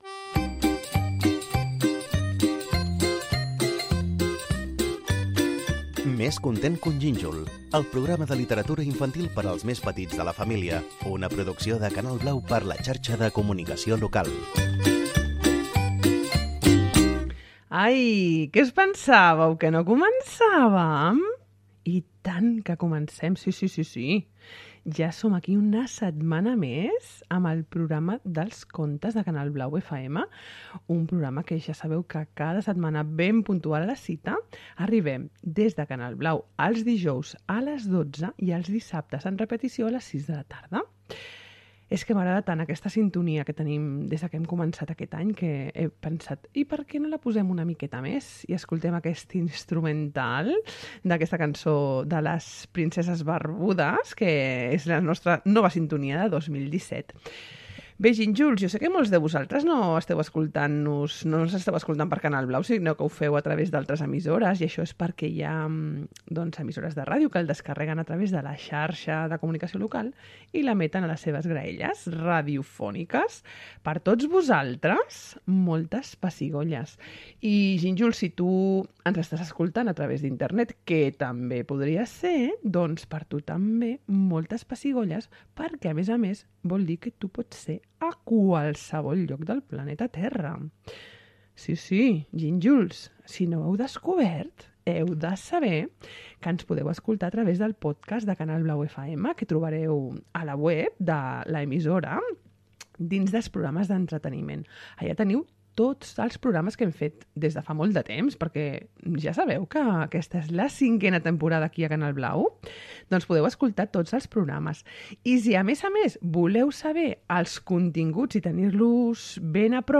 Careta, inici del programa sobre literatura infantil, formes com es pot escoltar el programa, poemari de cançons
Infantil-juvenil
Fragment extret de l'arxiu sonor de La Xarxa.